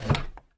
safebox_move.mp3